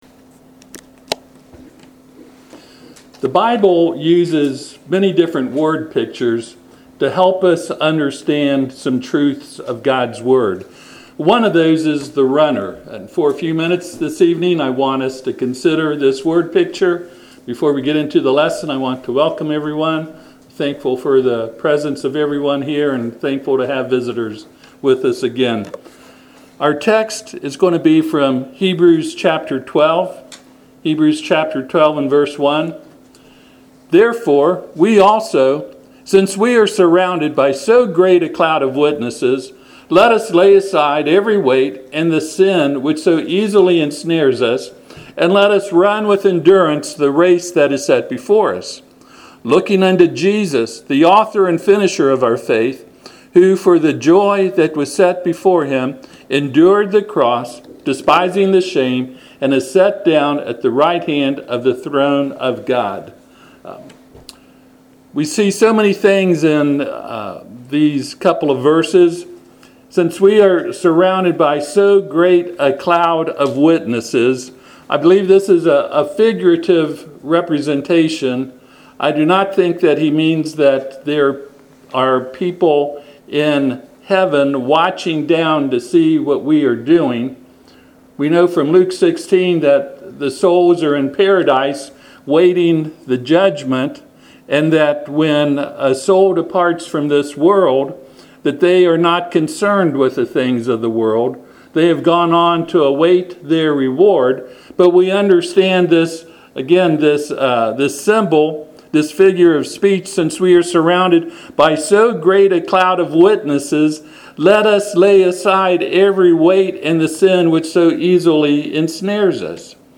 Passage: Hebrews 12:1-2 Service Type: Sunday PM